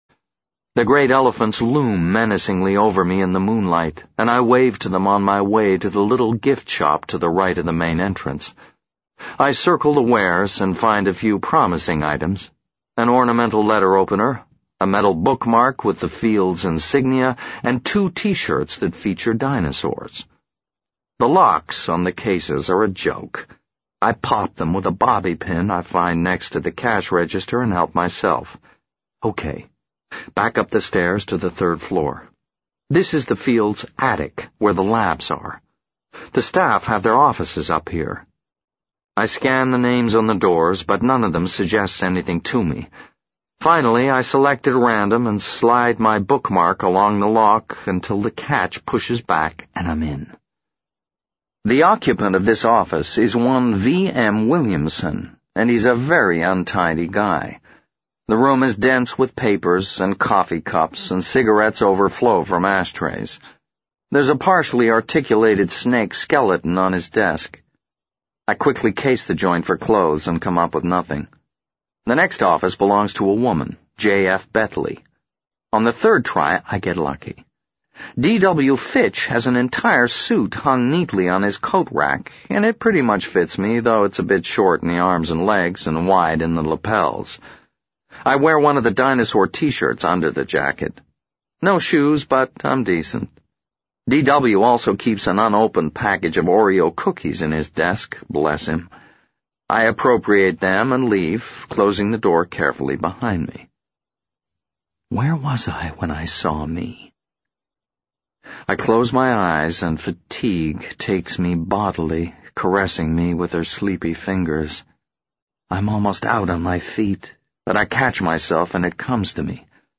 在线英语听力室【时间旅行者的妻子】25的听力文件下载,时间旅行者的妻子—双语有声读物—英语听力—听力教程—在线英语听力室